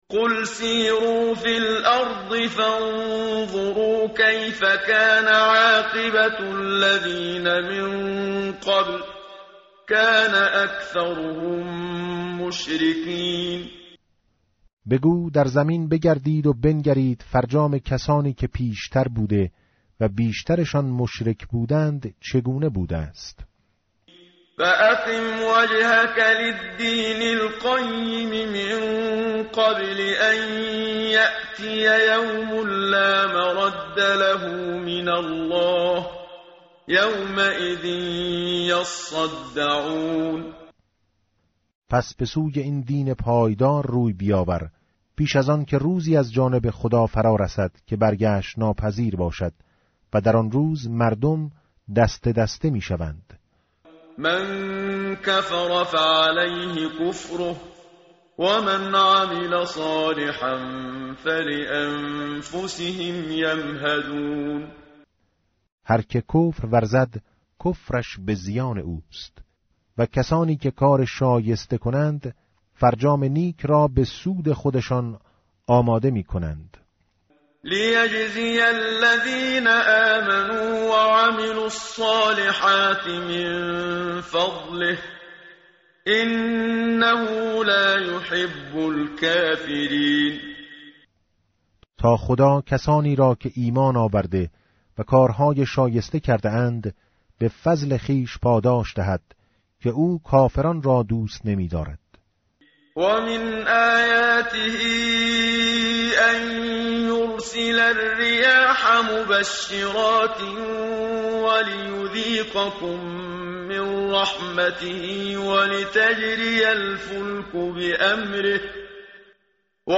متن قرآن همراه باتلاوت قرآن و ترجمه
tartil_menshavi va tarjome_Page_409.mp3